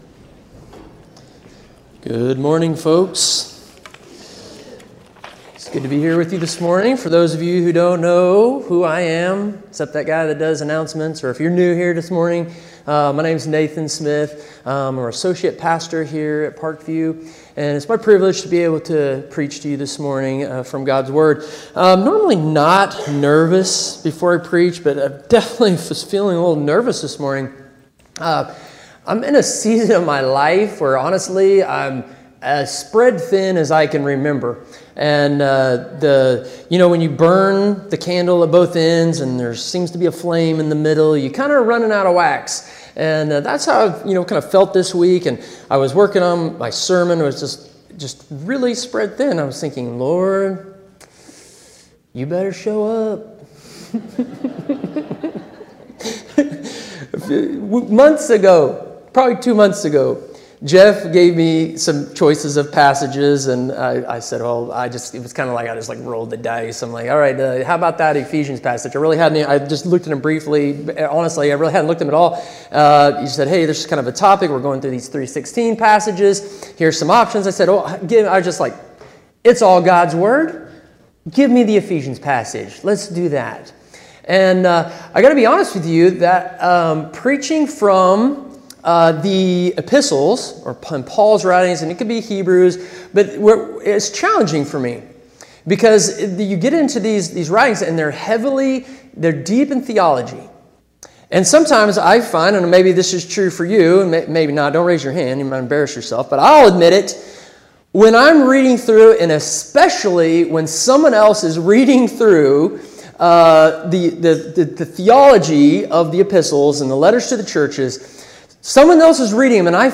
Sermon Summary Often, life feels more like a path strewn with obstacles and dangers than a relaxing sightseeing trip.